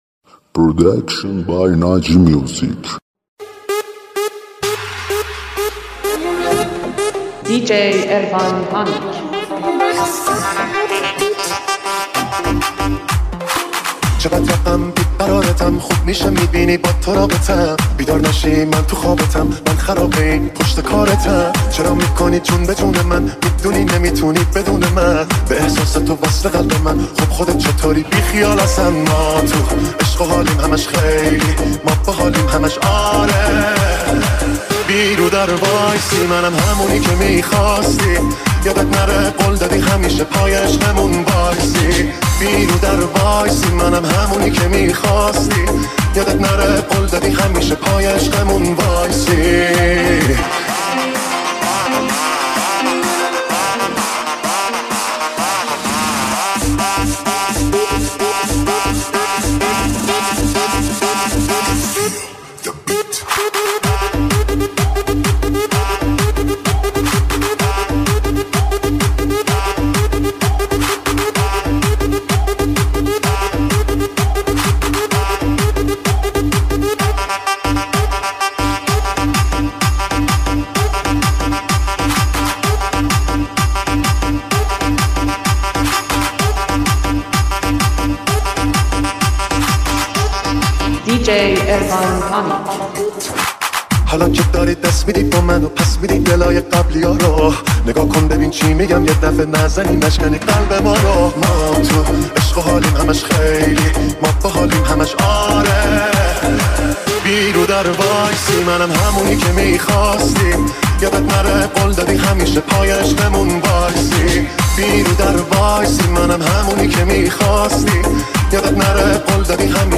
ریمیکس شاد جدید